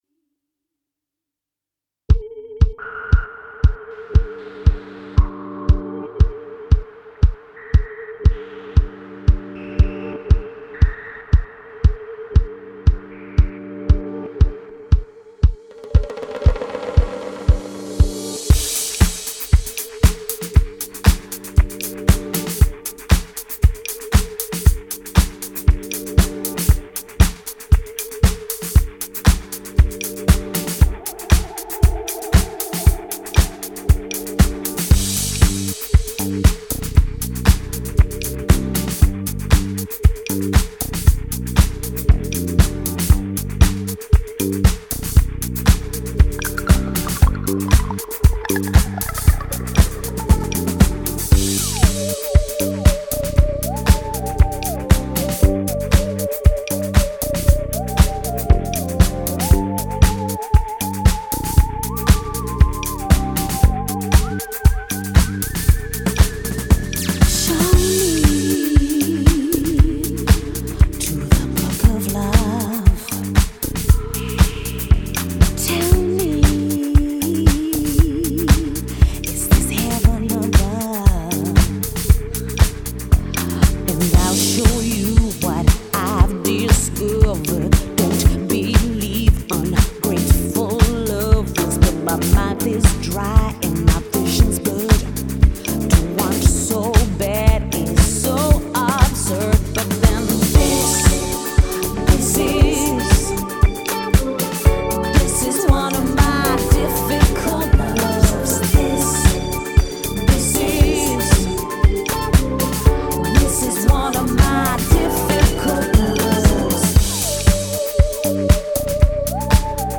London based duo
cinematic disco sound